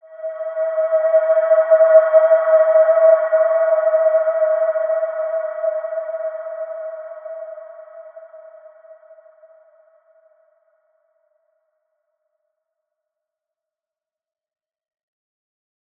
Large-Space-E5-mf.wav